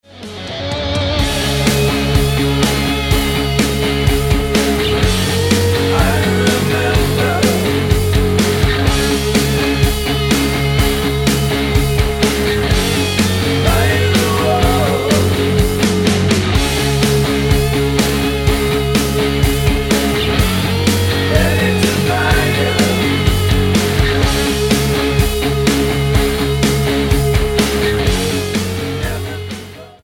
--> MP3 Demo abspielen...
Tonart:F# mit Chor